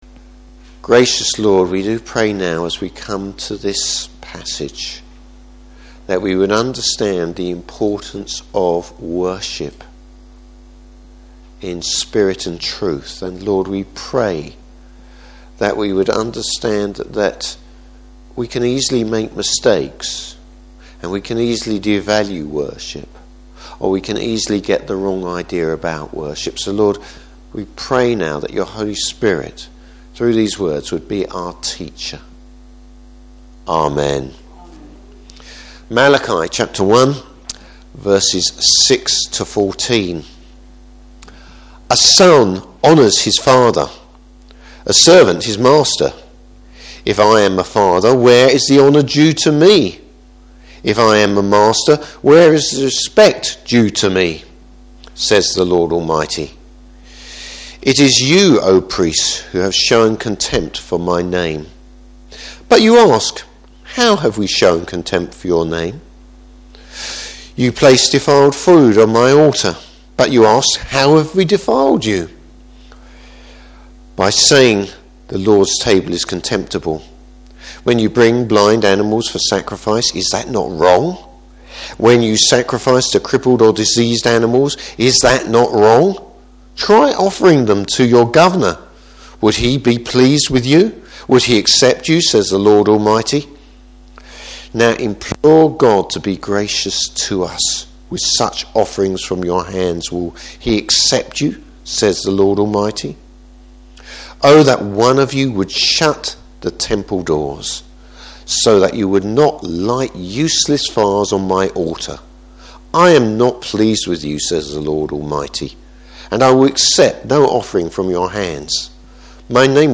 Service Type: Morning Service Why half hearted worship won’t cut it with the Lord.